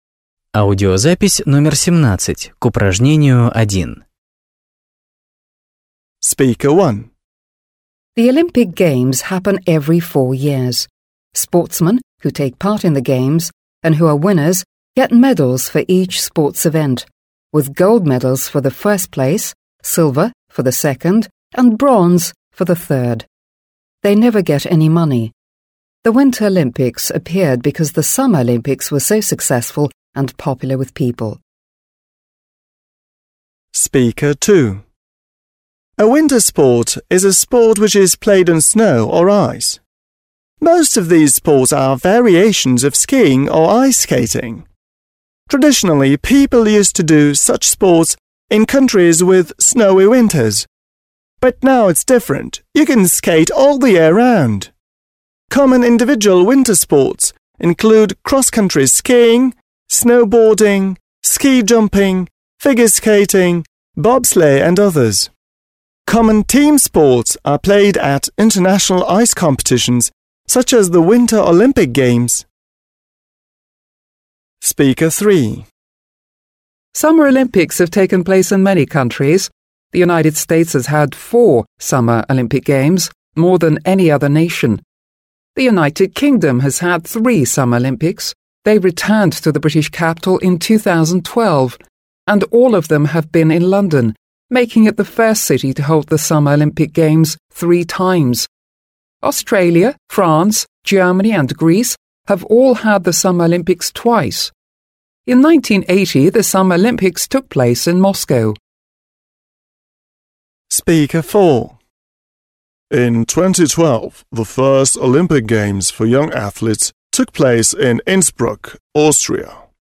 1. Listen, (17), and match the statements (a-e) with the information the speakers (1-4) tell.